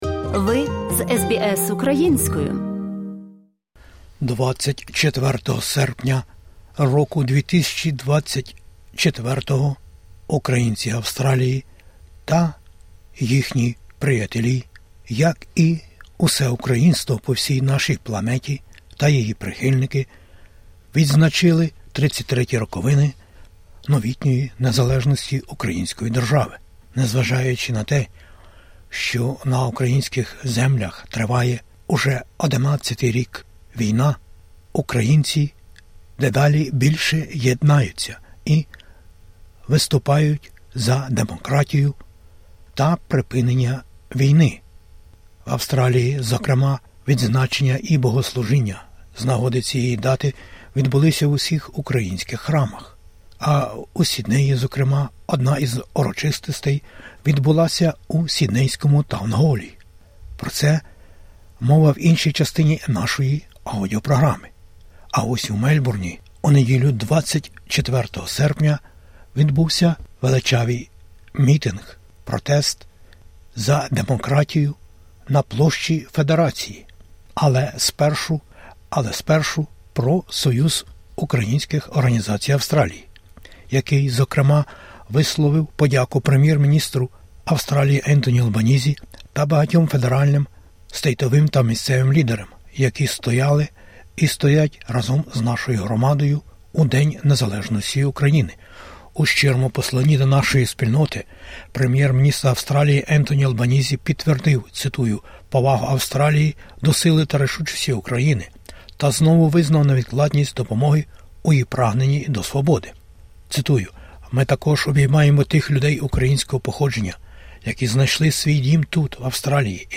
Мельбурн, 24 серпня: мітинґ на підтримку України, за припинення війни і демократію